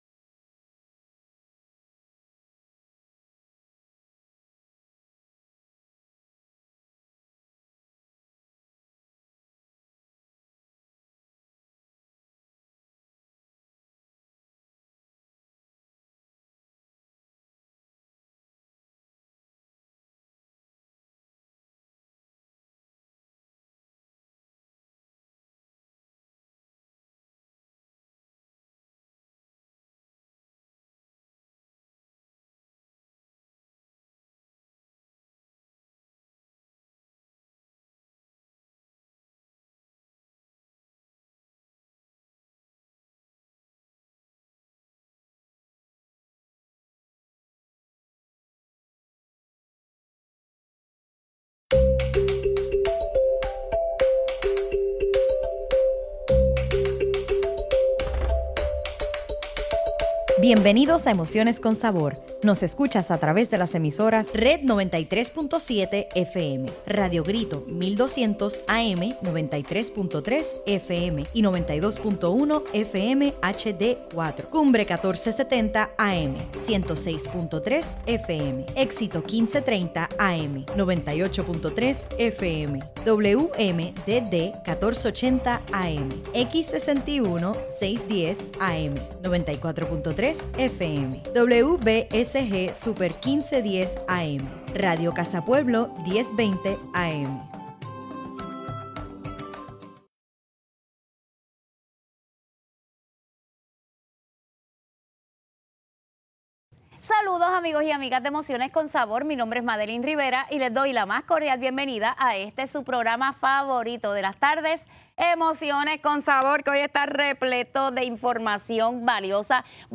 ¡Hoy en Emociones con Sabor por Radio!